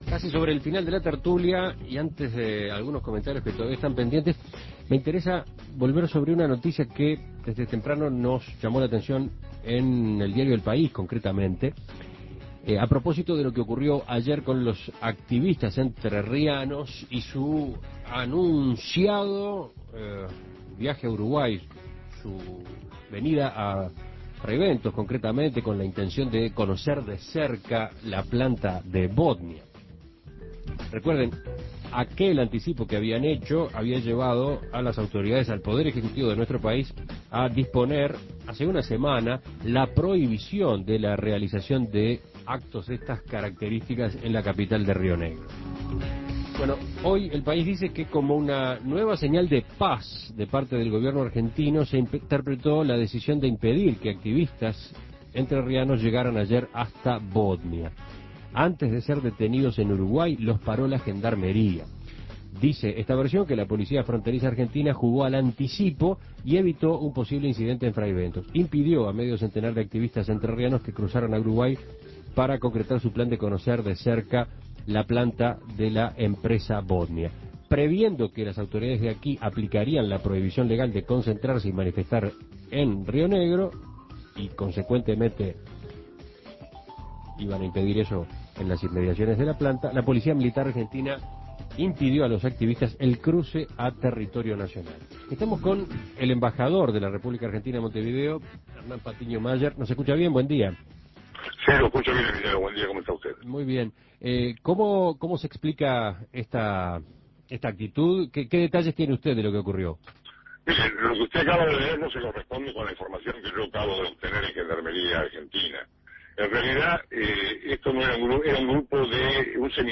Embajador argentino negó que Gendarmería haya impedido paso de activistas. Entrevista con Hernán Patiño Mayer.